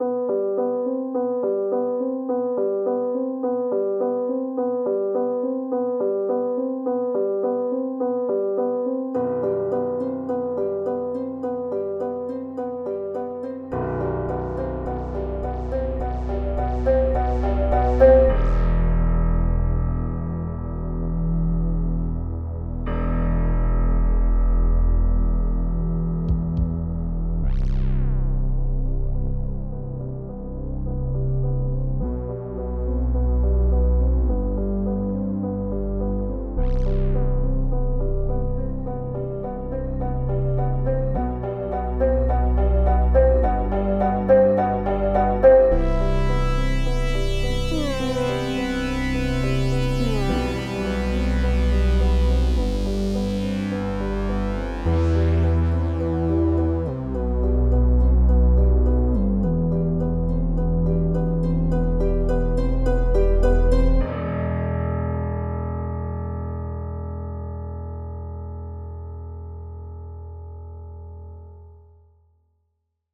A melancholy dark synthwave/newage song.